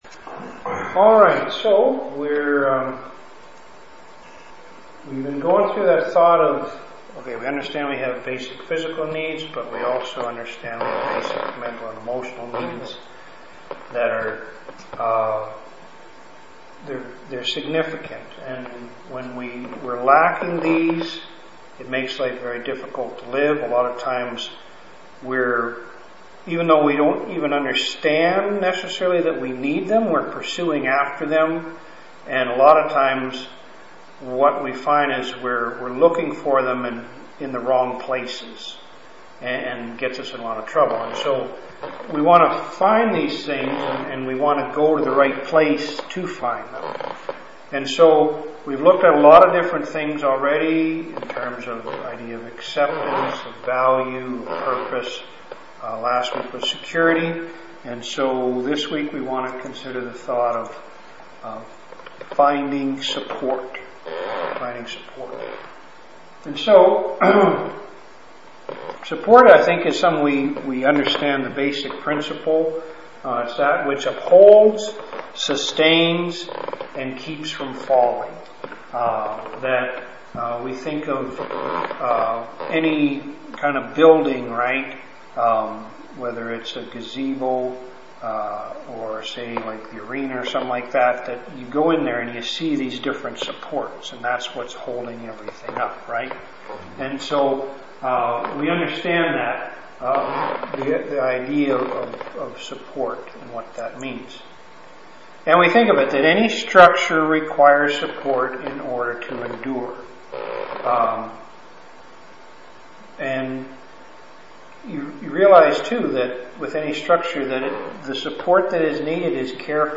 Multimedia Resources Audio, Video, Helpful Links and E-books RU Recovery Welcome Video To watch the welcome video, please click the following link: RU RECOVERY WELCOME VIDEO Audio Files At each RU Meeting we record Third Talk.